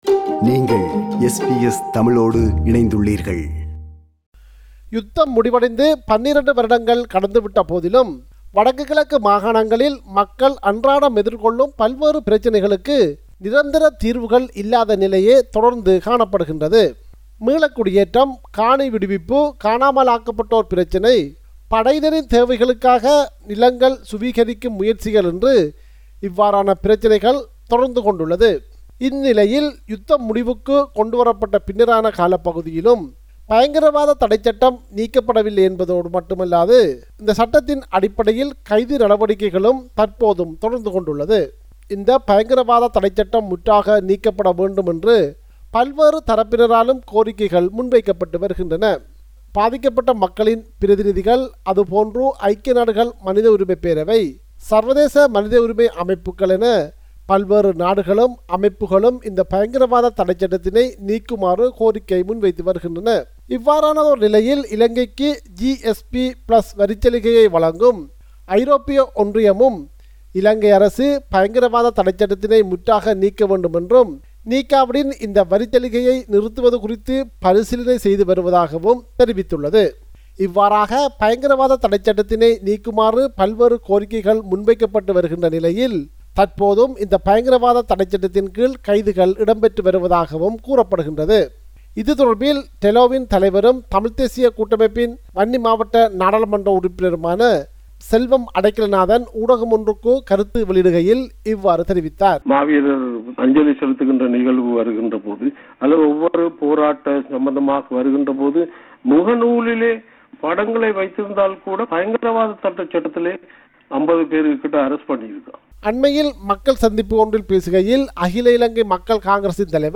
Protest in Sri Lanka Source